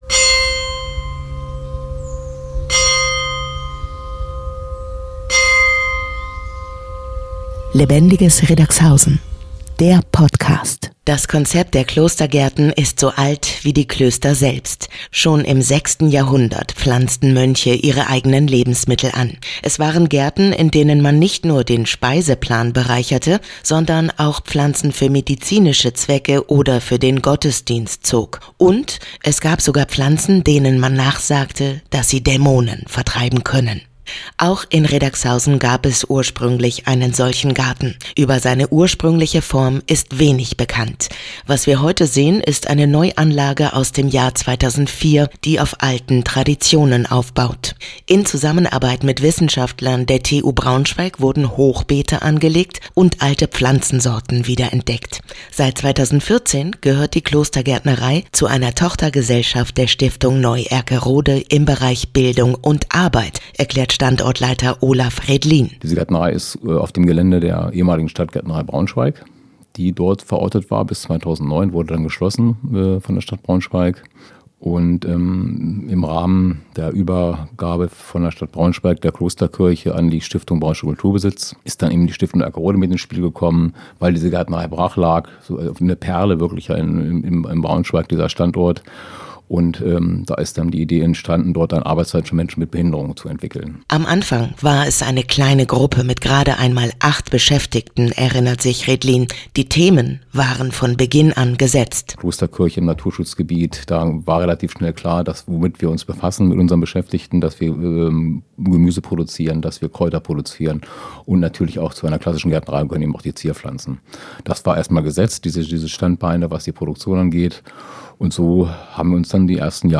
Das Interview hören Sie auch auf Radio Okerwelle.